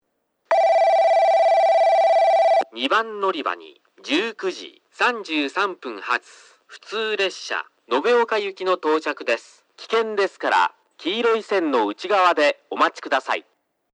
放送はJACROS簡易詳細型で、接近ベルが鳴ります。
スピーカーはFPS平面波です。なお放送の音割れが激しいですがこれは元からで、夜間音量の方が綺麗に聞こえます。
2番のりば接近放送（延岡行き）